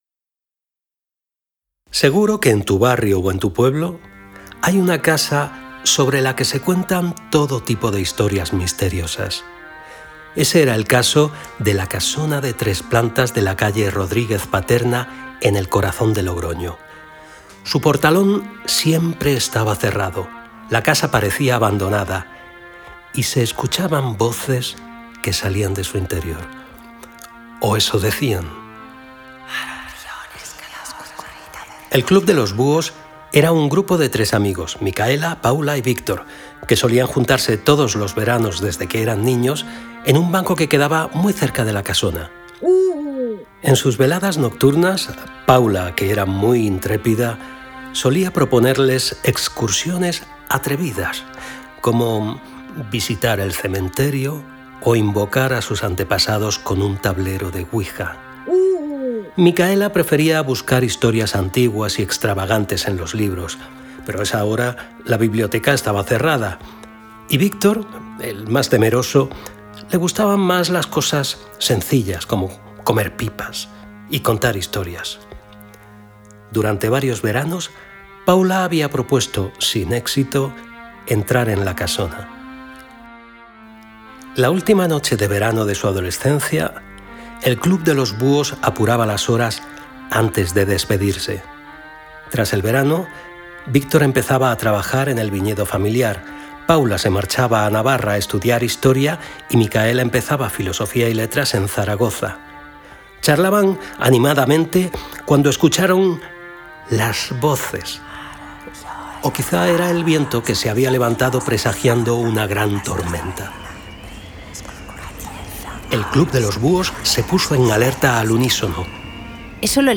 Sinopsis del cuento